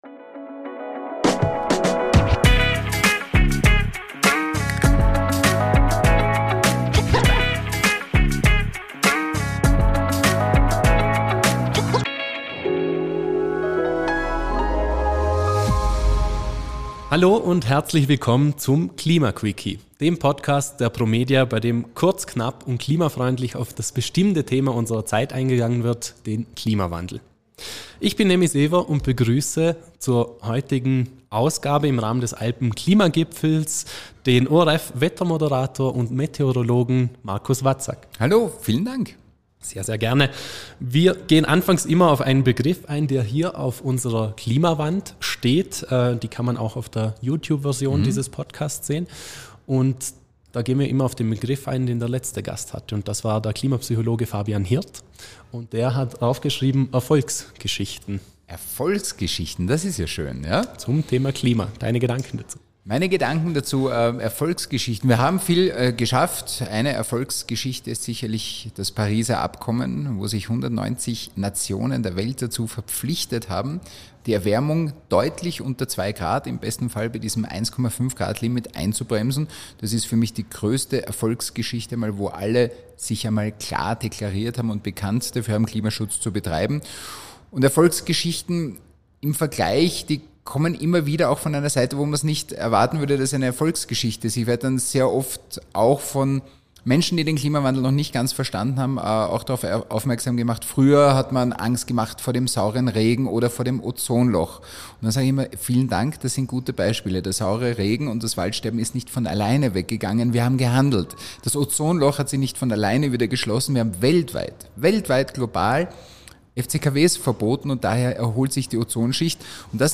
Wo steht Österreich derzeit im Kampf gegen den Klimawandel? Marcus Wadsak, ORF Wettermoderator und Meteorologe, geht im Podcast auf Wetterveränderungen und Klimaszenarien der Zukunft ein. Im Rahmen des ersten AlpenKlimaGipfels auf der Zugspitze spricht er darüber, welche Techniken er bei der Wettermoderation nutzt, welche Entwicklungen er sieht und was getan werden muss, um die Klimaziele nicht zu verfehlen.